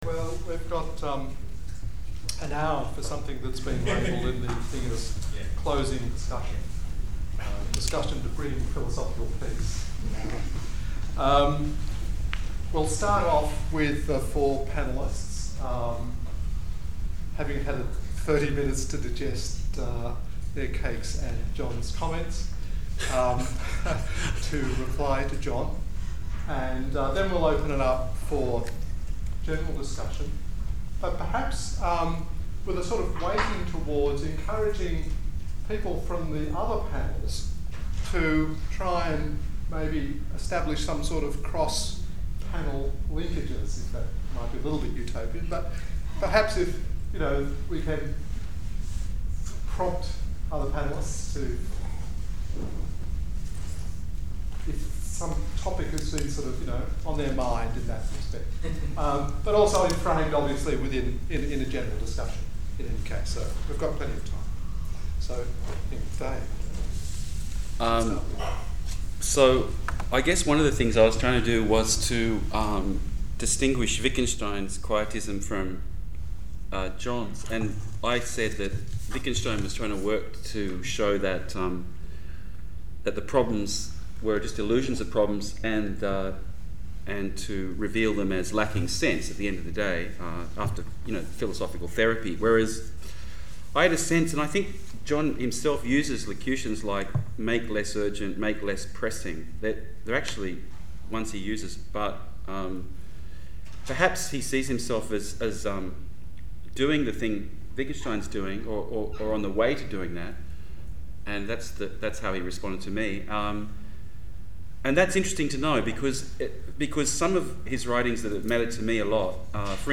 RoundTable.mp3